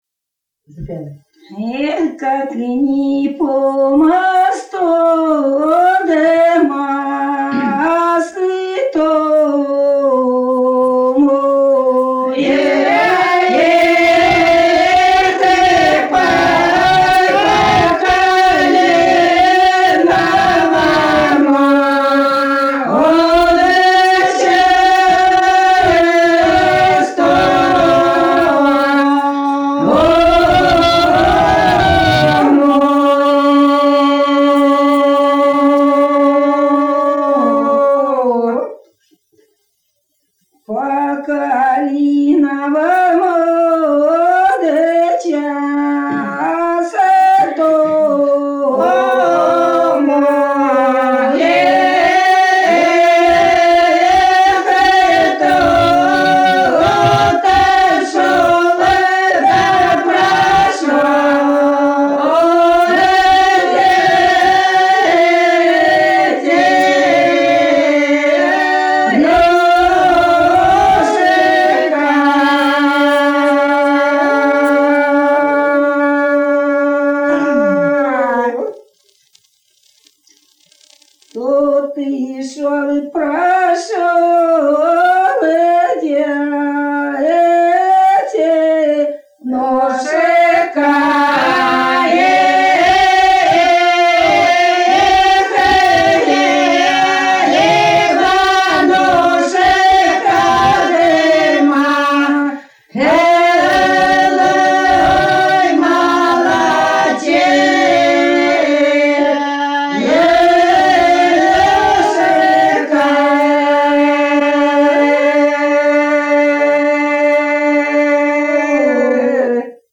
01_-_01_Как_не_по_мосту,_мосточку,_свадебная.mp3